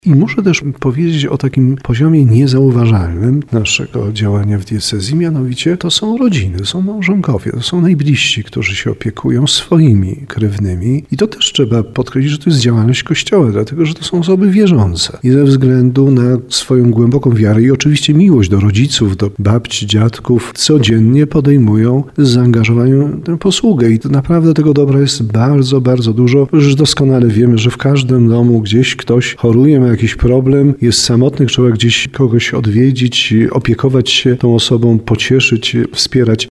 Biskup tarnowski Andrzej Jeż podkreśla, że widzi także jak wiele dobra ma miejsce w rodzinach.